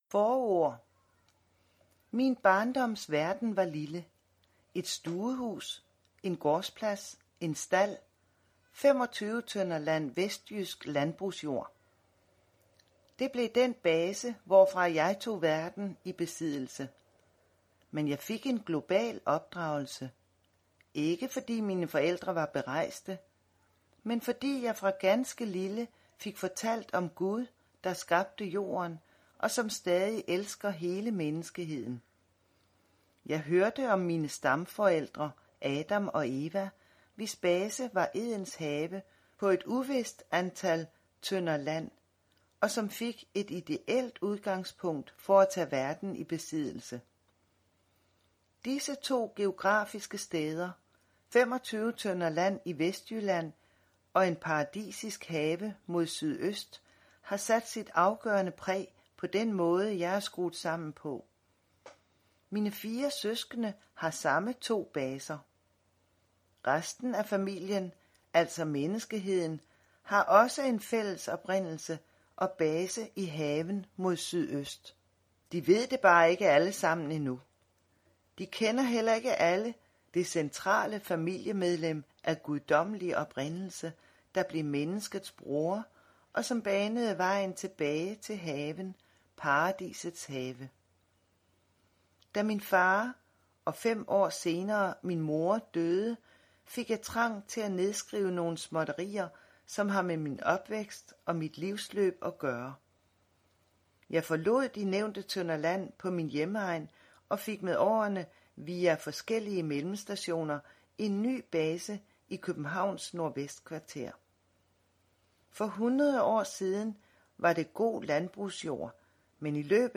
Hør et uddrag af Femogtyve tønder land Femogtyve tønder land nordvest for Paradis Format MP3 Forfatter Børge Haahr Andersen Lydbog 49,95 kr.